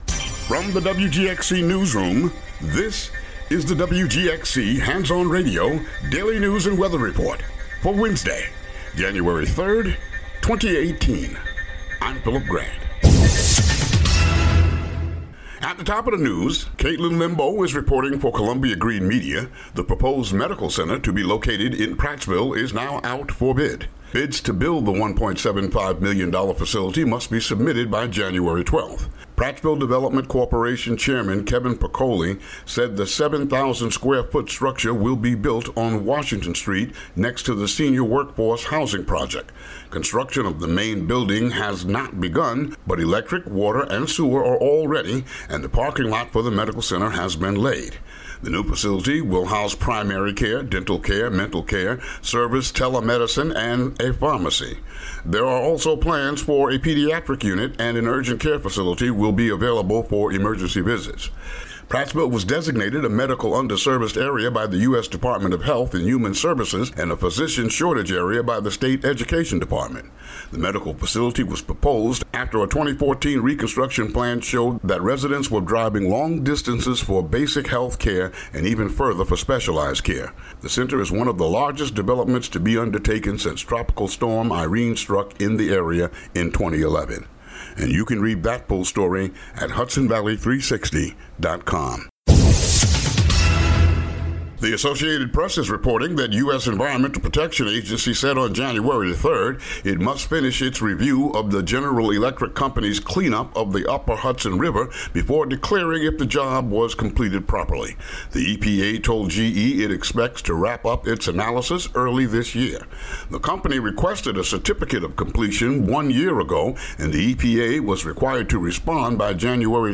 Daily local news for Wed., Jan. 3.